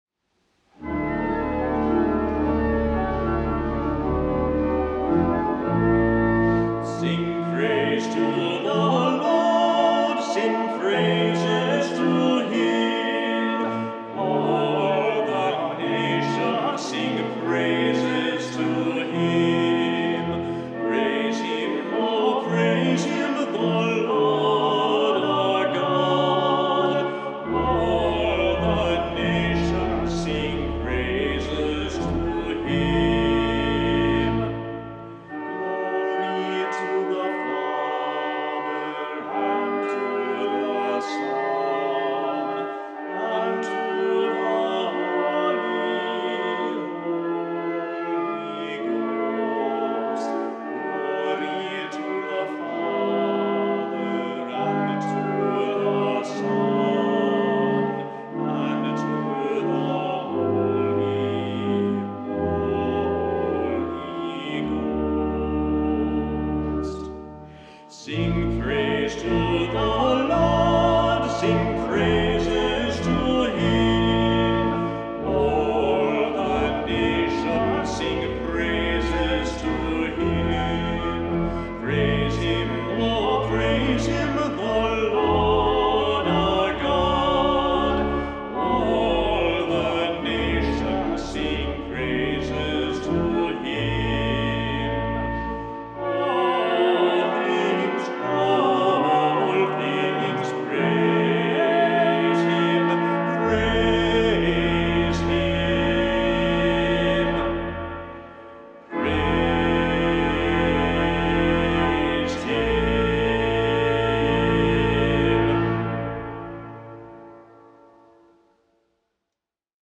The organ has 62 stops and close to 3,200 pipes.
On October 3, 2022, we recorded the following hymns at the Basilica of Our Lady of Perpetual Help:
organ